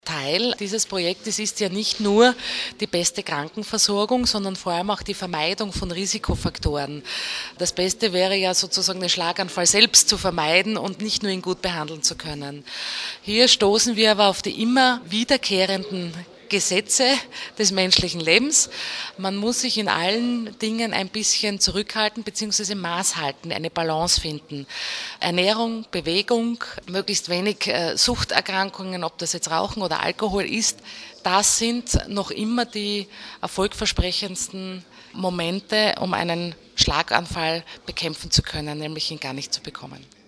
Pressekonferenz zum Thema integrierte Versorgung von Schlaganfallpatienten